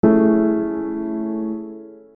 A Major 7
The added major seventh enhances the basic A major triad with a layer of sophistication and complexity. This interval doesn't create significant tension or dissonance; instead, it enriches the chord's color, adding a subtle warmth and resonance that deepens the emotional impact of the tonic.